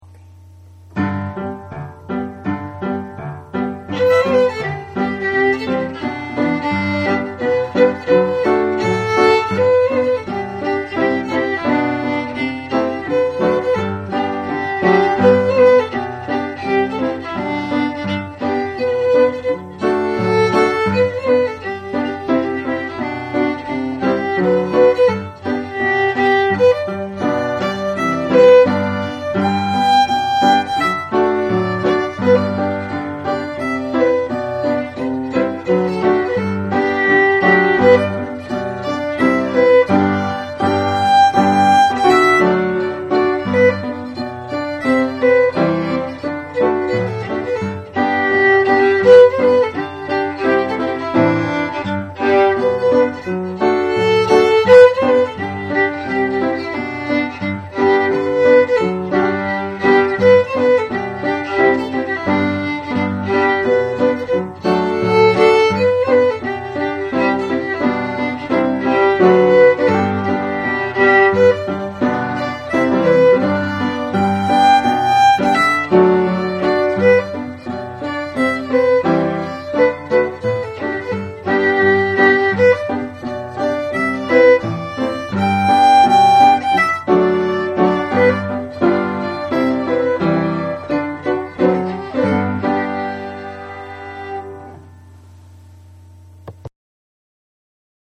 Reel - G Major